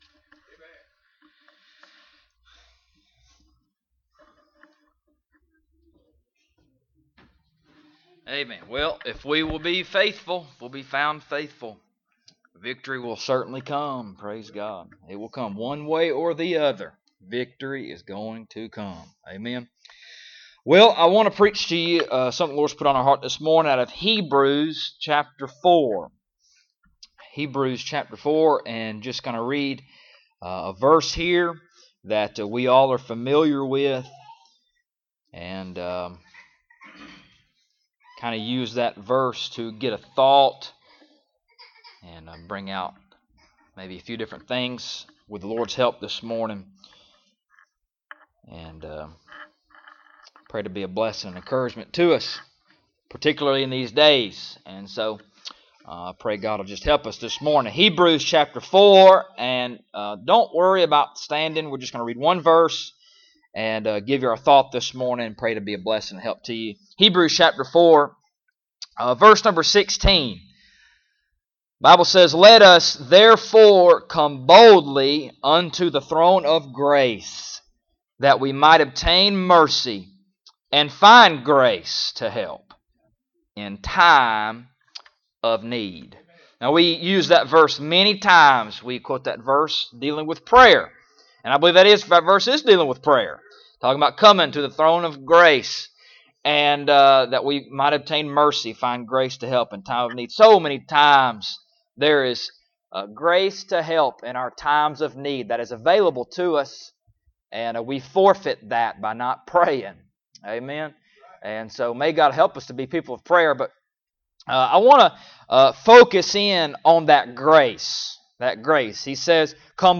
Hebrews 4:16 Service Type: Sunday Morning Bible Text